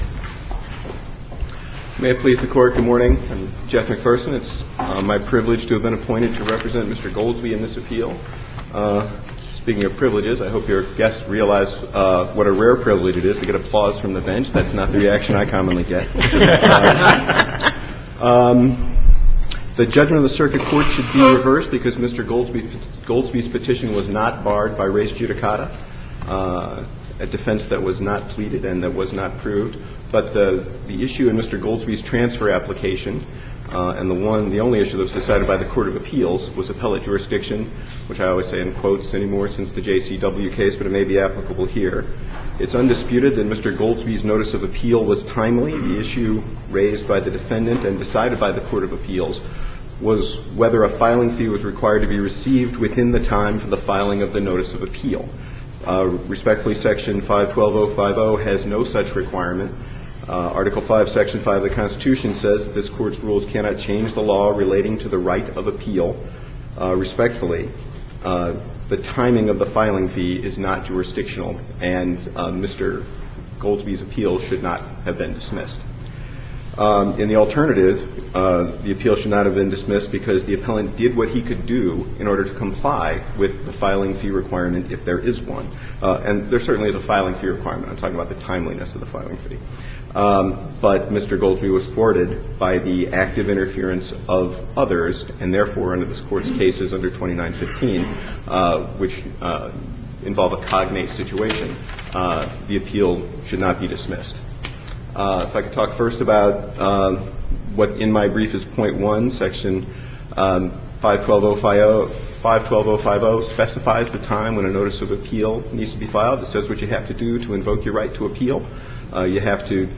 MP3 audio file of arguments in SC96639